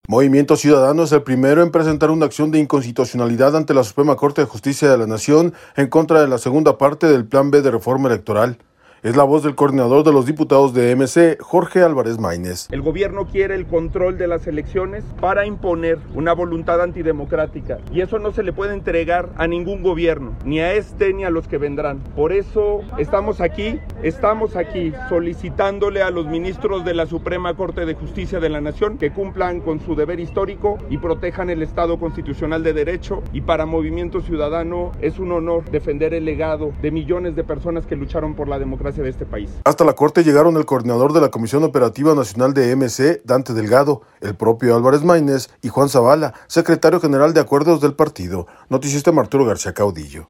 Es la voz del coordinador de los diputados de MC, Jorge Álvarez Maynez.